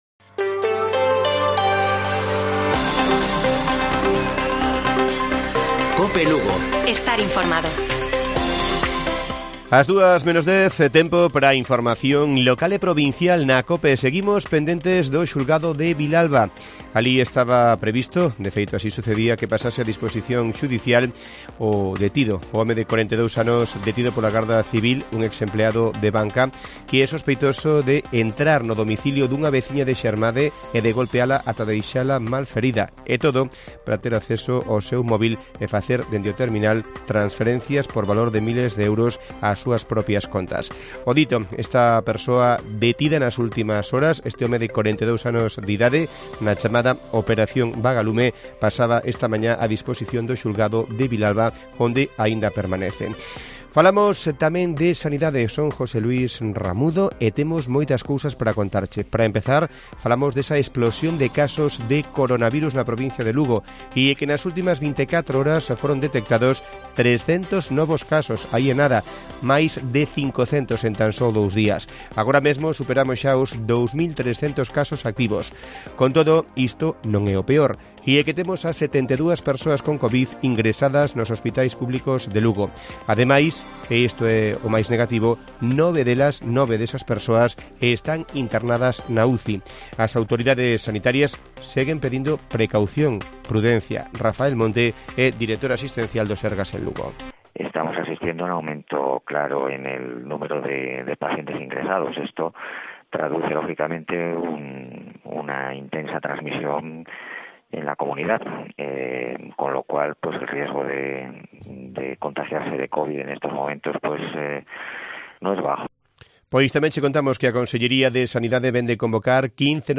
Informativo Mediodía de Cope Lugo. 27 DE ABRIL. 13:50 horas